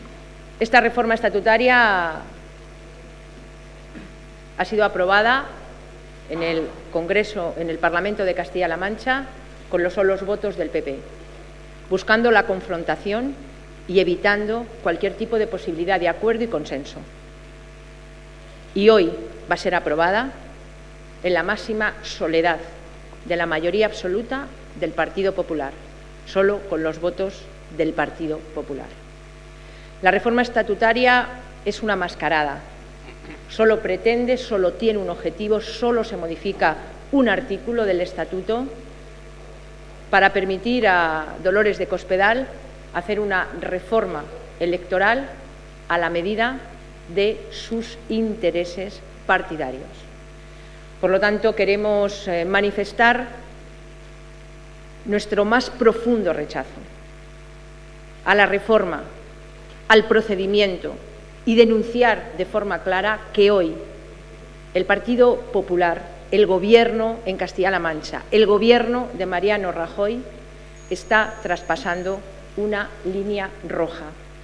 Declaraciones de Soraya Rodríguez en el Congreso sobre la reforma del estatuto de Castilla-La Mancha 13/03/2014